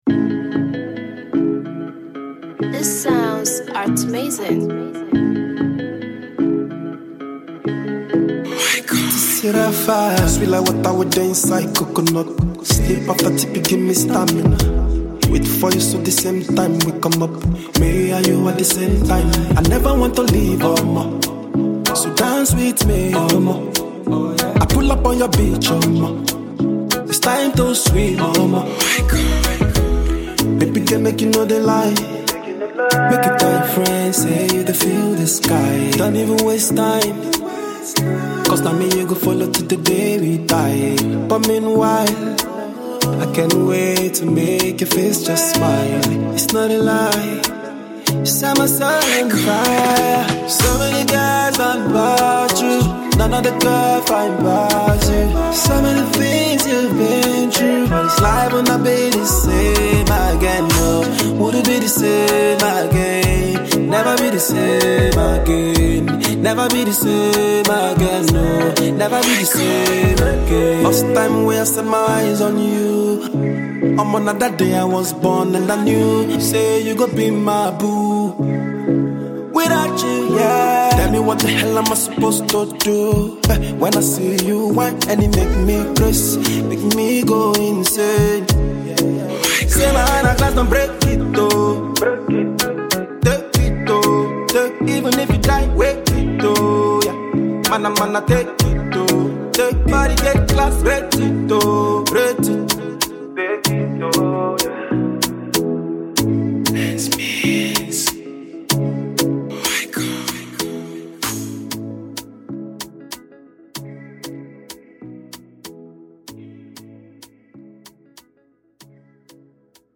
• Genre: Afro-pop